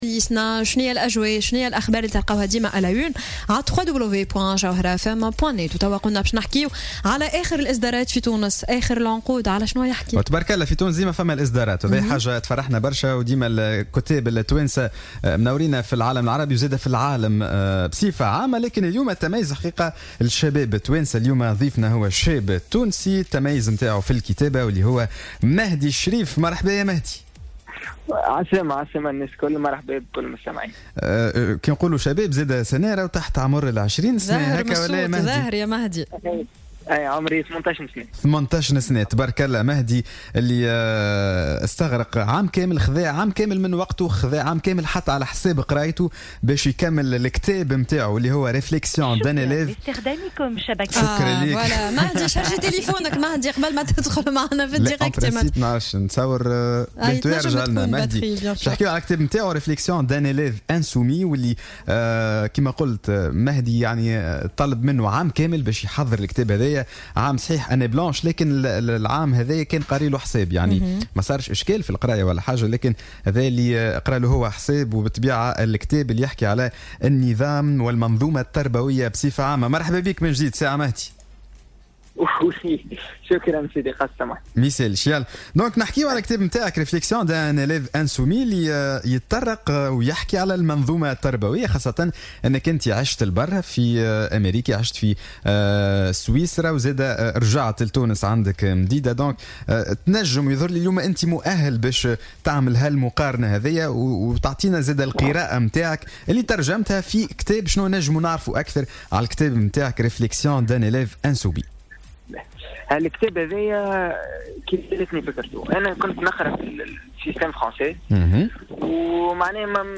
في مداخلة له في صباح الورد اليوم الثلاثاء